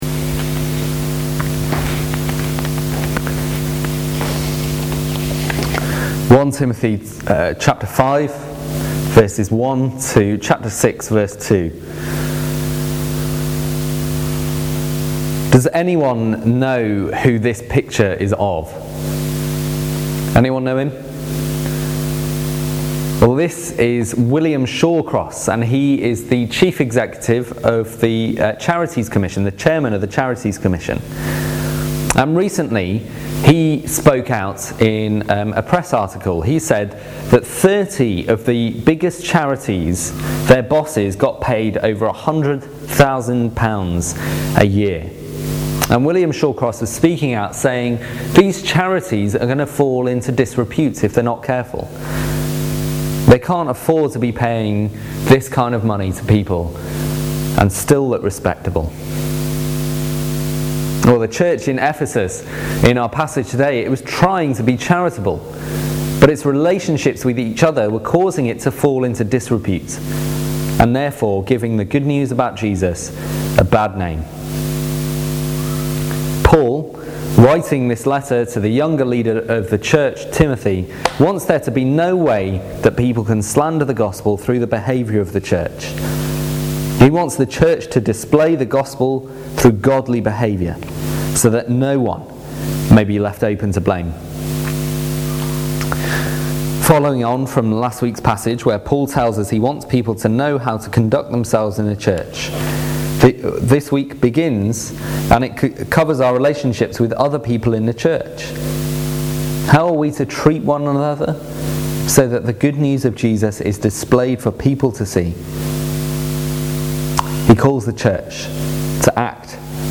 We recently worked our way through Paul’s first letter to Timothy. You can listen to the talks below.